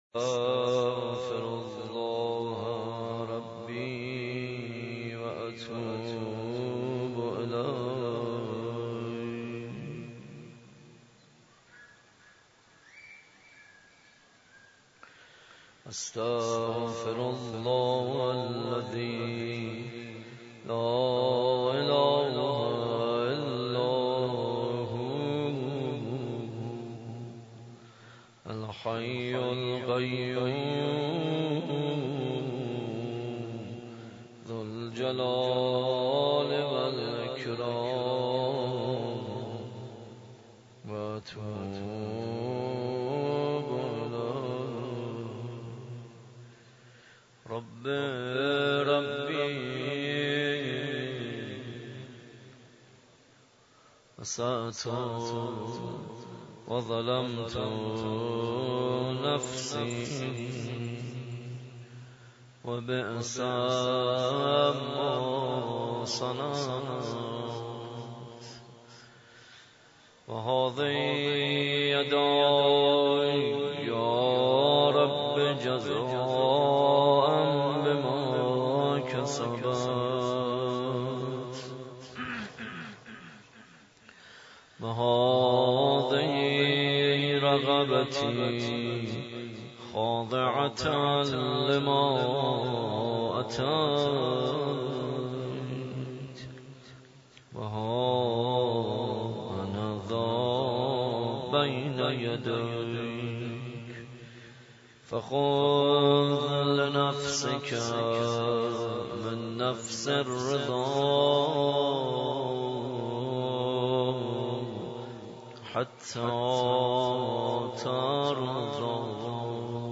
در مسجد امام حسین (ع) واقع در میدان امام حسین(ع) برگزار گردید.
کد خبر : ۵۸۰۷۶ عقیق:صوت این جلسه را بشنوید شب بیست و هشتم ماه مبارک رمضان دعای افتتاح لینک کپی شد گزارش خطا پسندها 0 اشتراک گذاری فیسبوک سروش واتس‌اپ لینکدین توییتر تلگرام اشتراک گذاری فیسبوک سروش واتس‌اپ لینکدین توییتر تلگرام